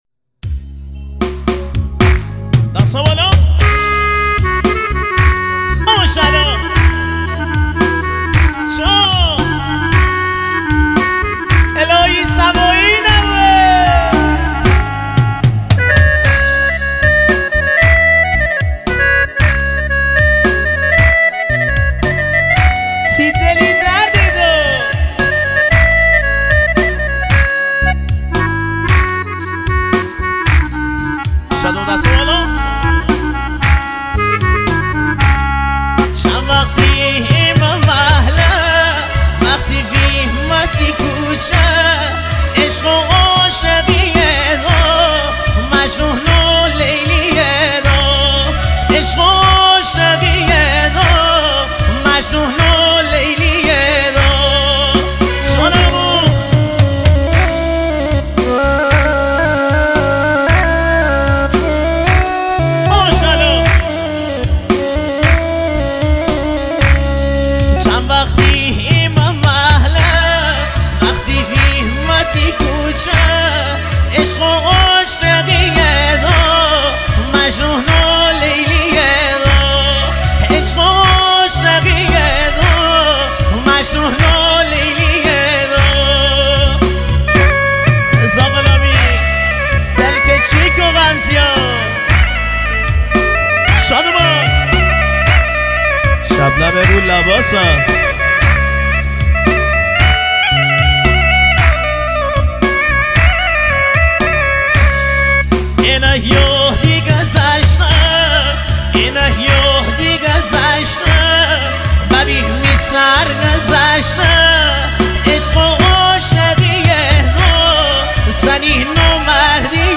آهنگ زیبای مازندرانی
آهنگ شاد